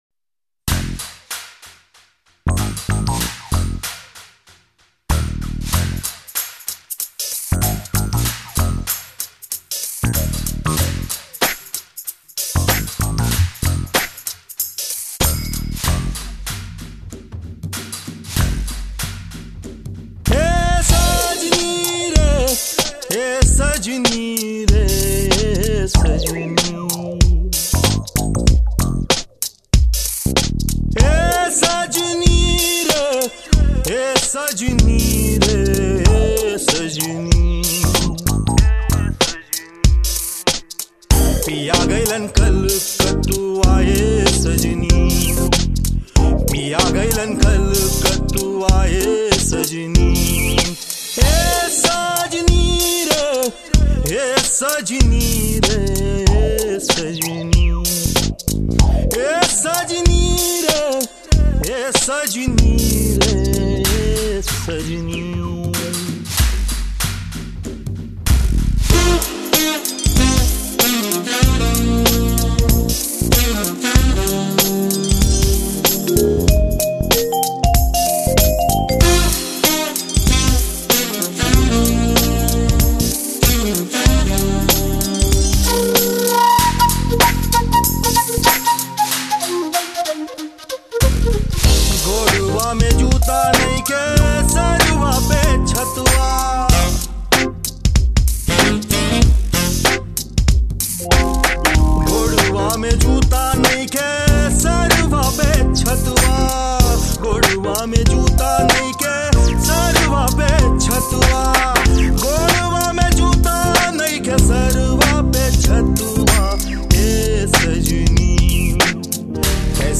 with Beats